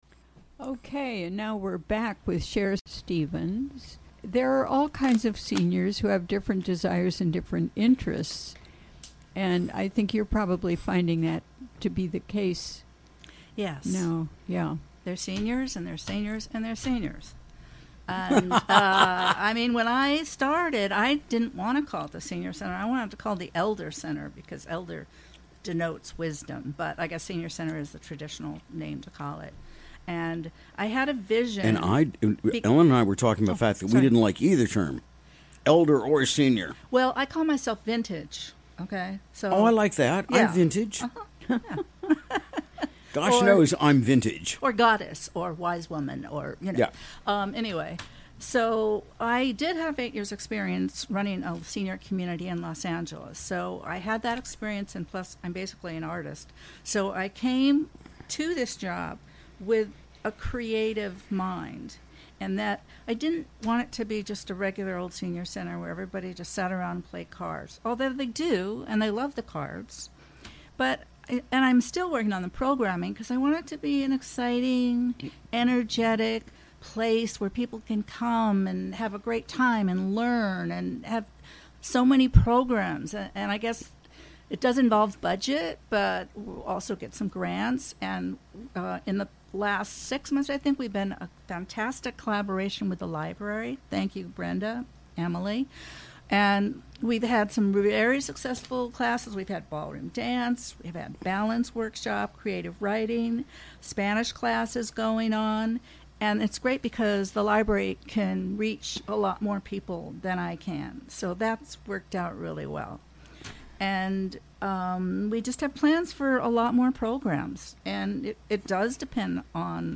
Recorded during the WGXC Afternoon Show on October 5, 2017.